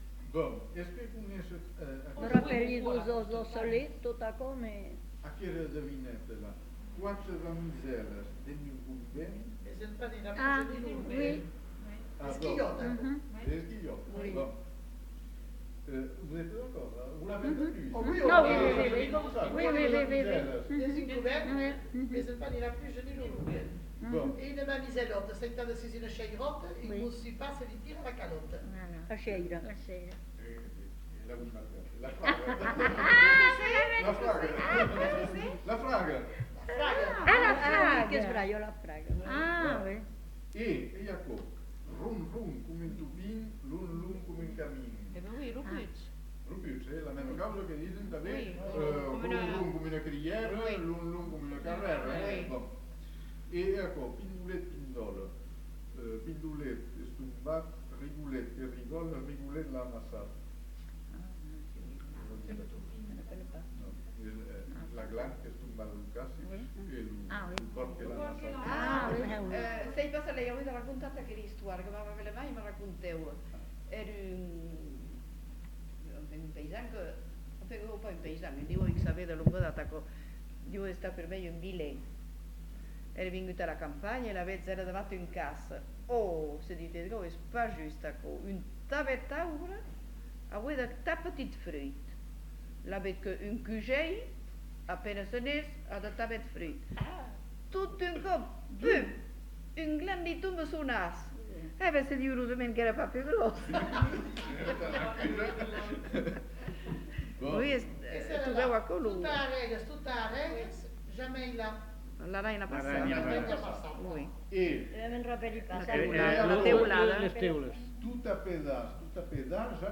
Lieu : Uzeste
Genre : forme brève
Effectif : 1
Type de voix : voix d'homme
Production du son : récité
Classification : devinette-énigme